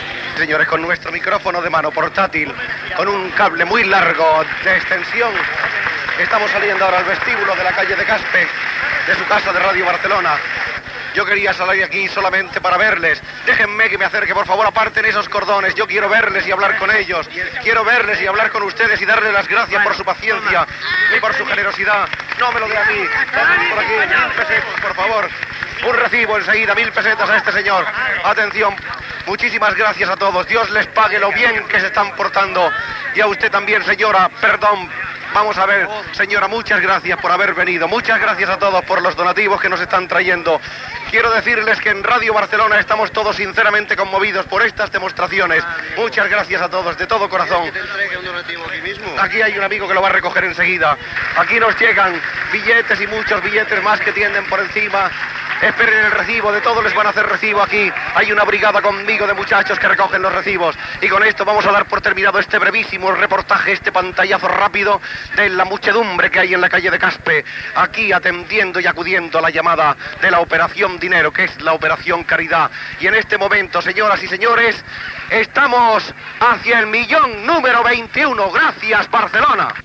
Últim dia de la programació especial per recaptar diners en benefici de les persones afectades per la riuada del Vallès. Sortida de Soler Serrano a la porta de l'emissora del carrer de Casp.